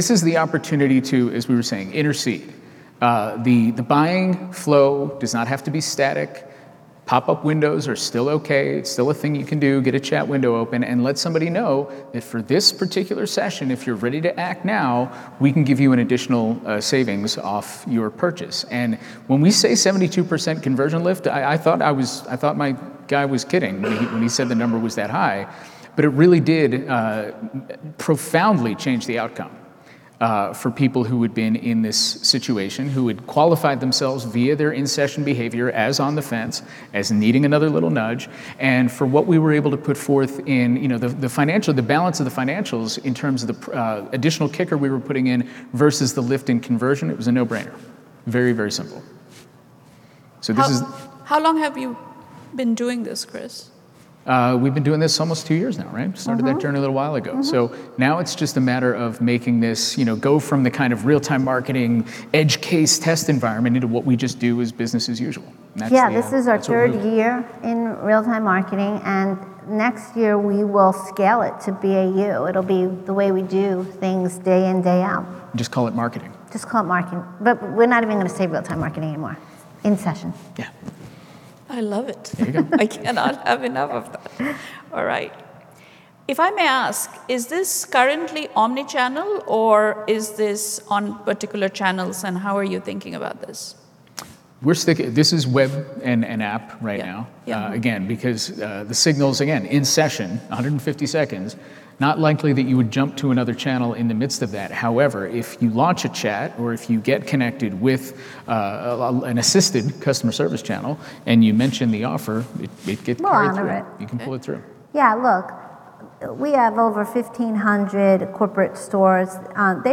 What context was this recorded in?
Adobe Summit